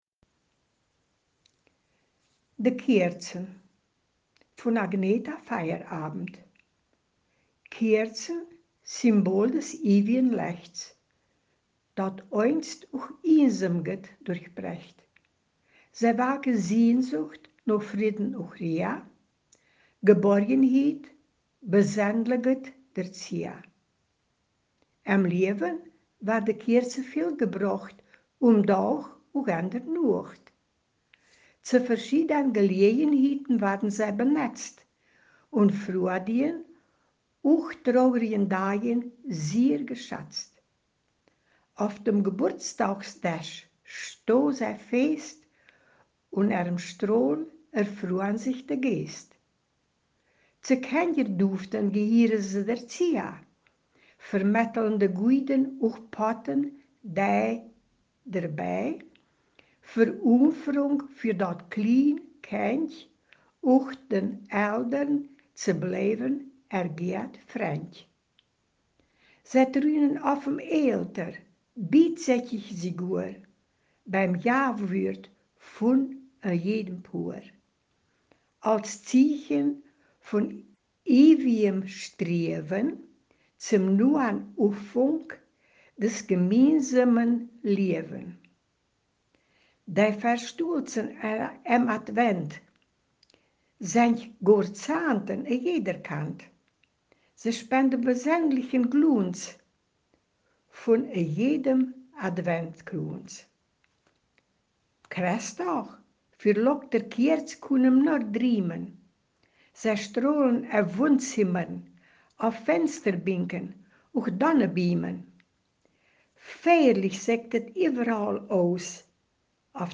Ortsmundart: Hamlesch